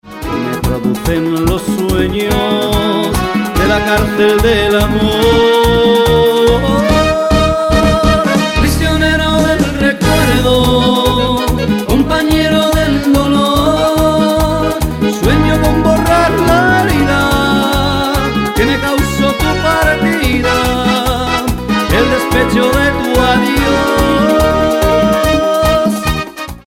es un merengue en toda regla